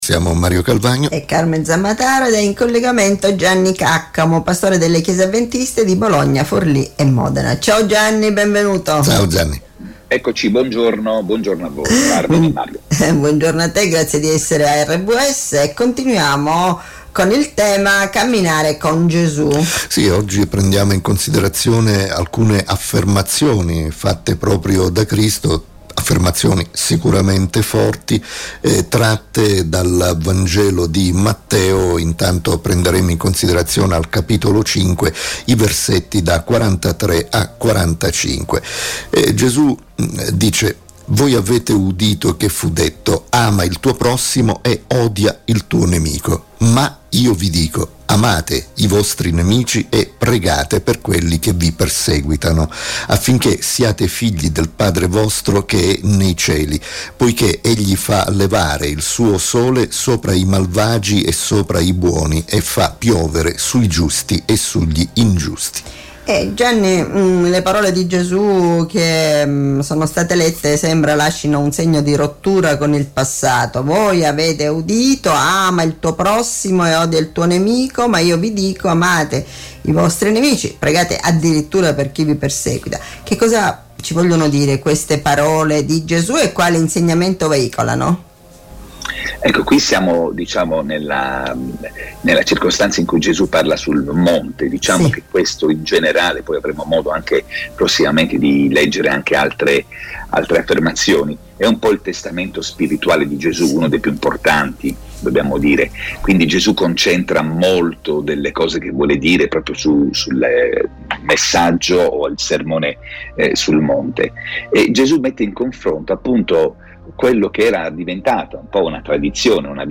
Una storia commentata